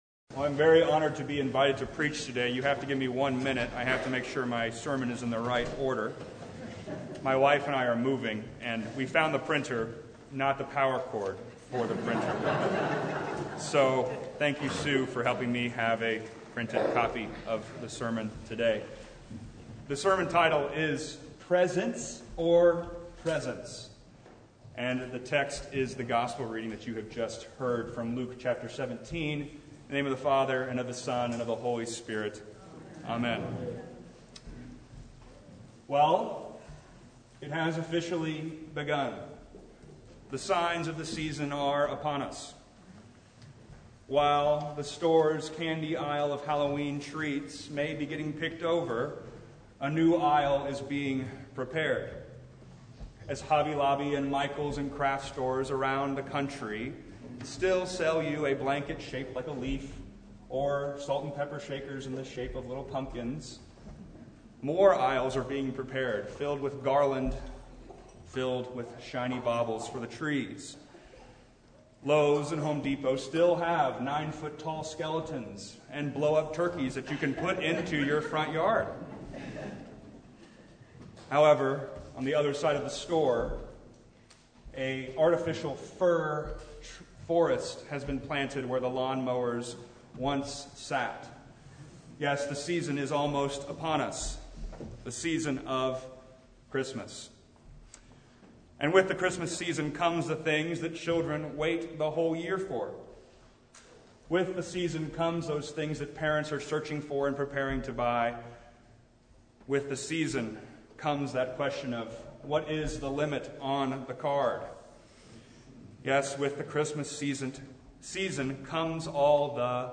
Preacher: Visiting Pastor Passage: Luke 17:11–19